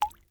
water-drop-03-2
Category 🌿 Nature
bath bathroom bubble burp click dribble dribbling drip sound effect free sound royalty free Nature